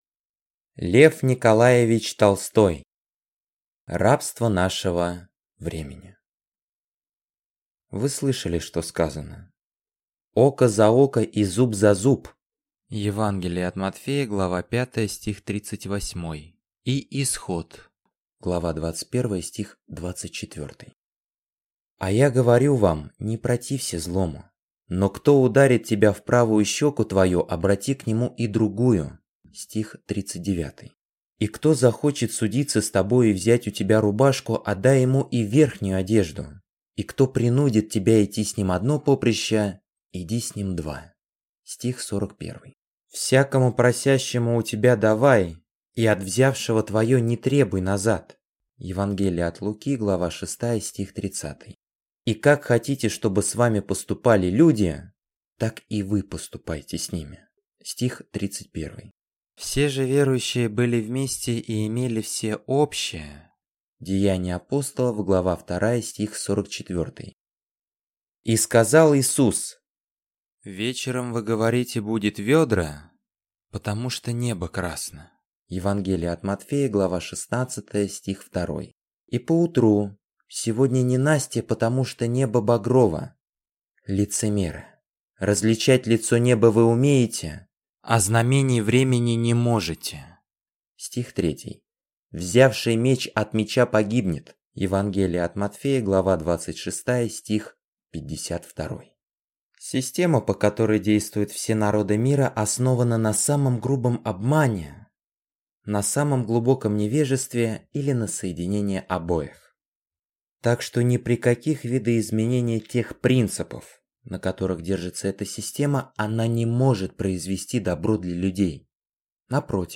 Аудиокнига Рабство нашего времени | Библиотека аудиокниг